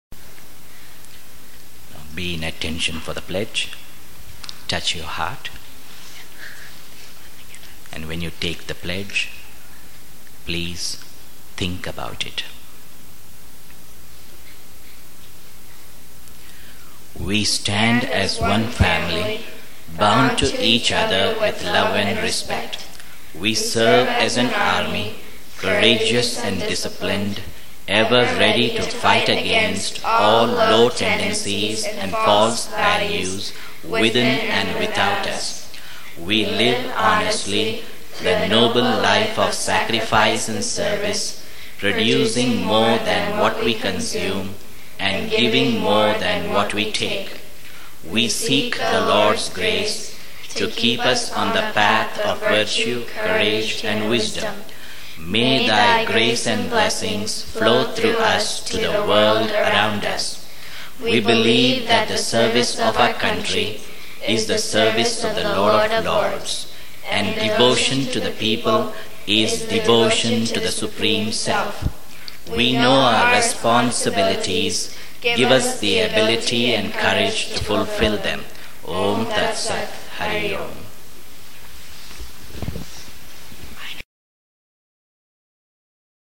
with Children